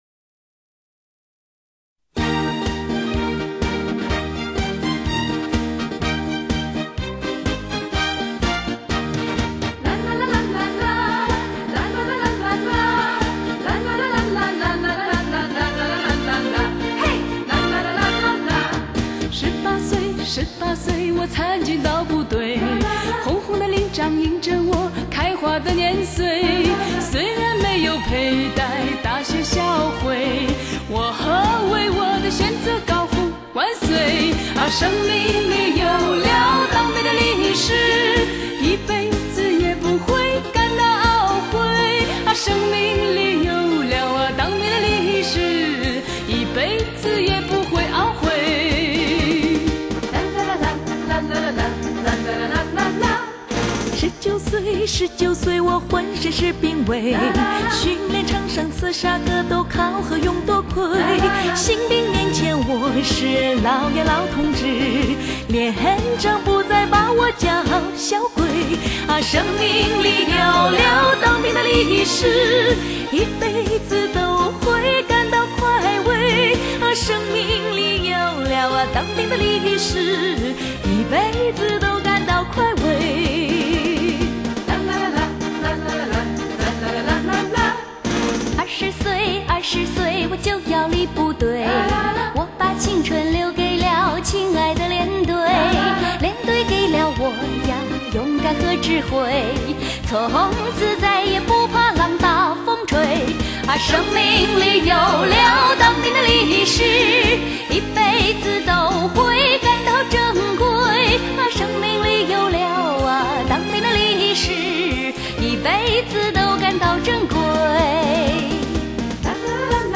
激昂高亢、催人奋进的军歌，凝聚了为自由解放而并肩作战战士的力量；